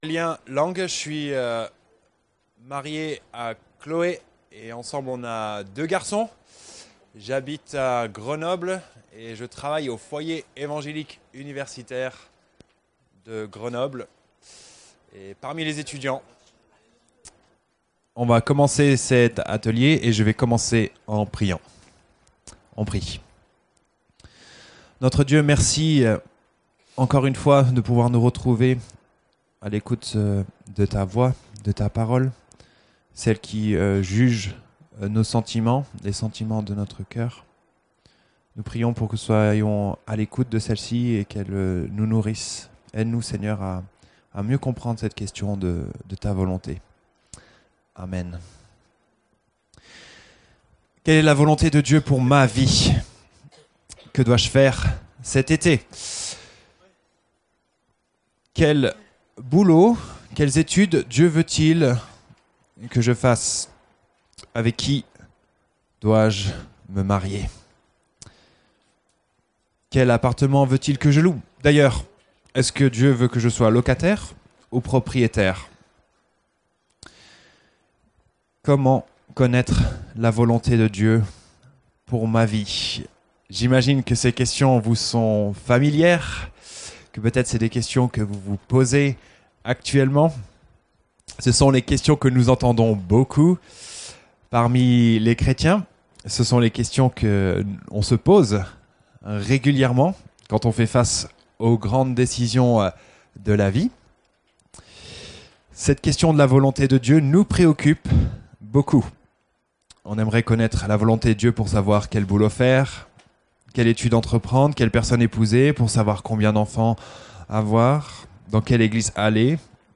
Pâques 2019 - Ateliers